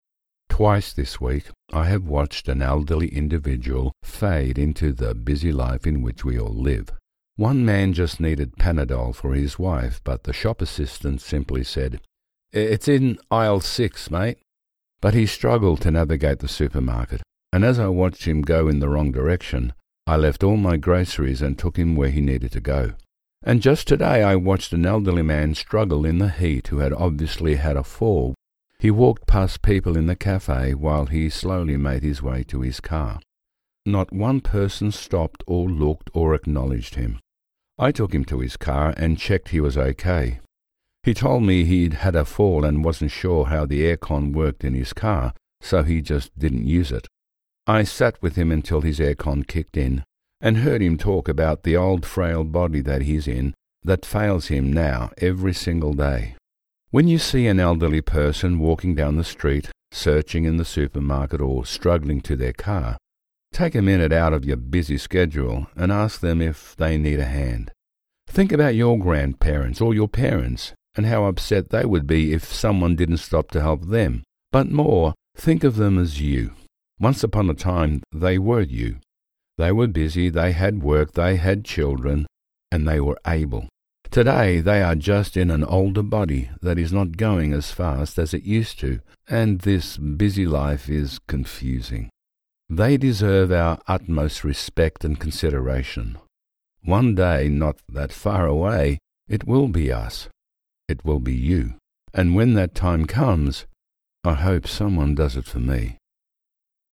Male
English (Australian)
Older Sound (50+)
Narration
Words that describe my voice are Warm, Corporate, Authoritative.